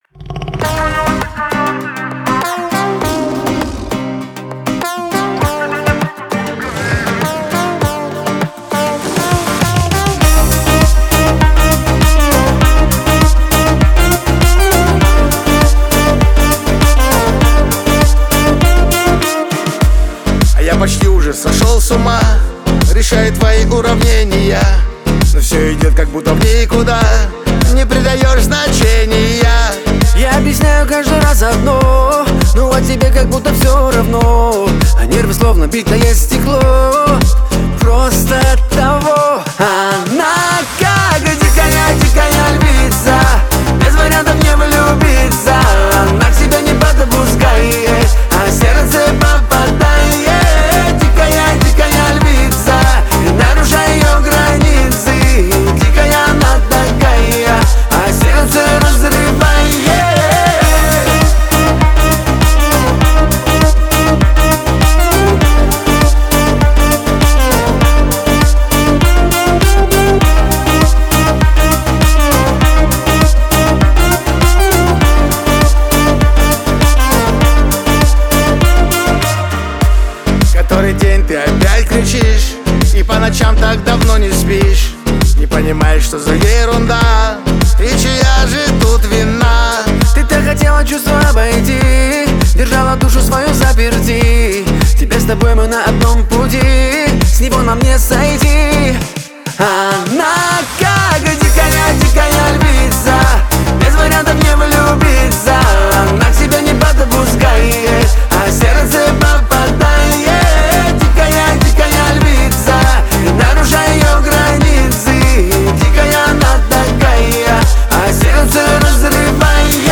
Танцевальная музыка
русские песни